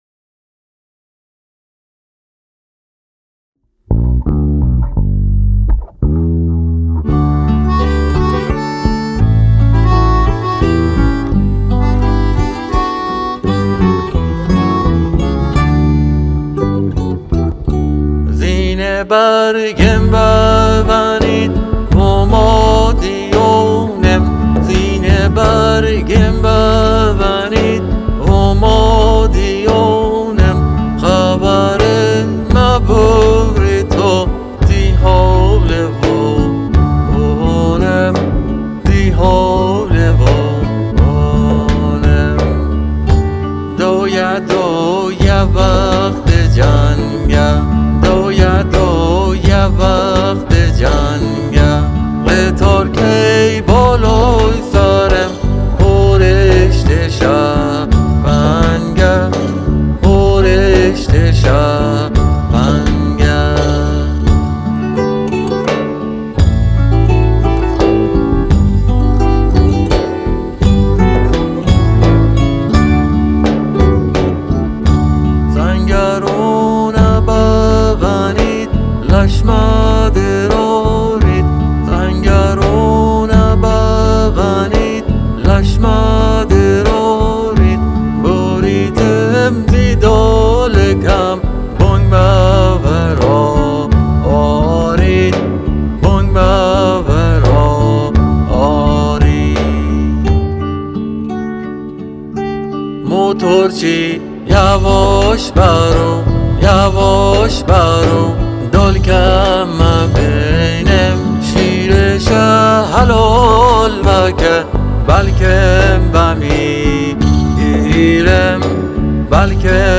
• بازآفرینی ملودیک، گیتار و ملودیکا
• تنظیم، میکس و گیتار باس